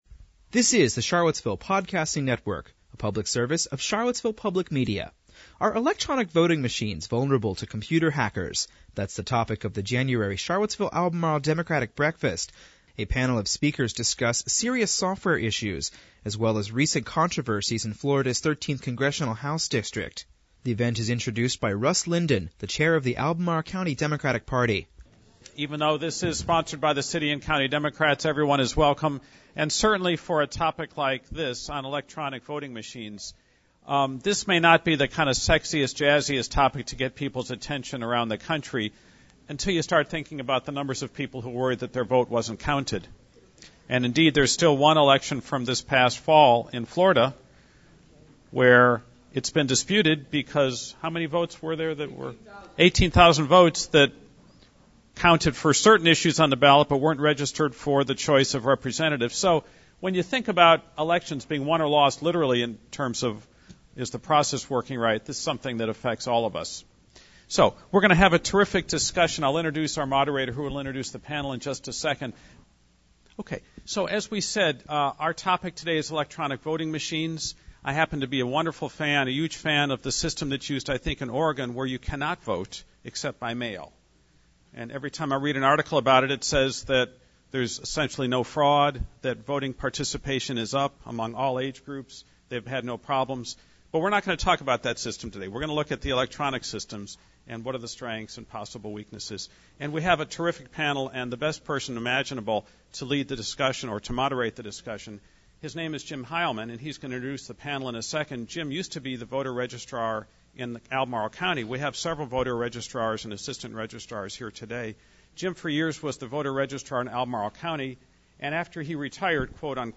Are electronic voting machines vulnerable to computer hackers? That’s the topic of the January Charlottesville Albemarle Democratic Breakfast. A panel of speakers discuss serious software issues as well as recent controversies.